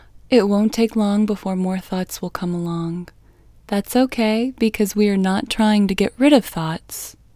LOCATE OUT English Female 17